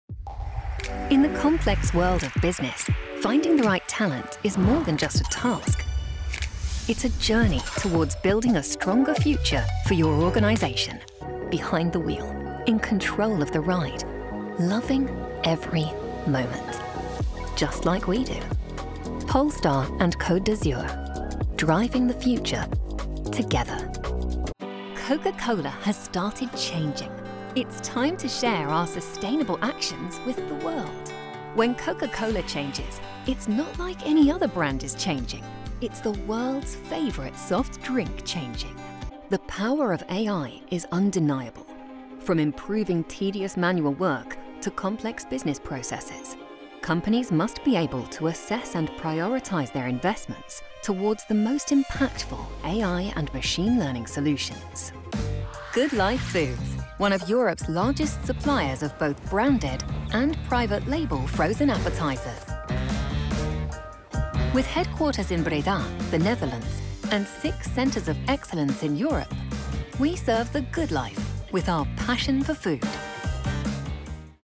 Inglés (Reino Unido)
Vídeos corporativos
Micrófono de condensador Neumann TLM 103
Cabina insonorizada con calidad de transmisión con paneles acústicos GIK